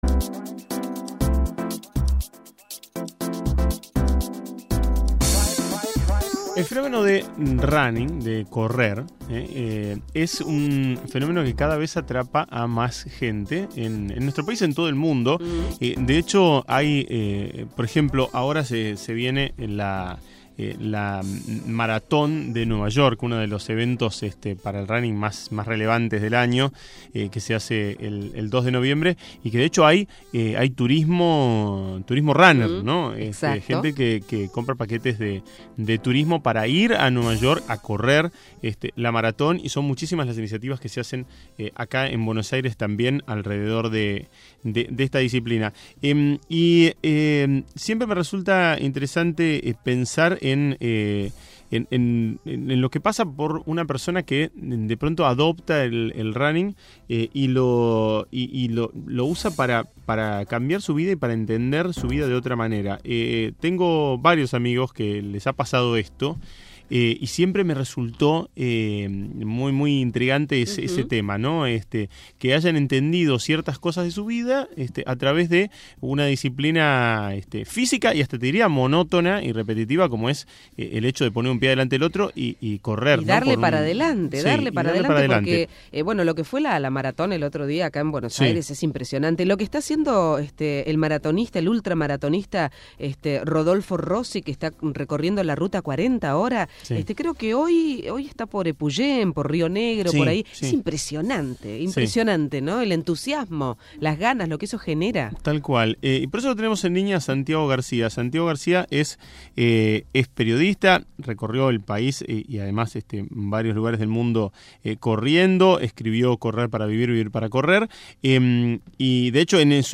Entrevista en Tiempos líquidos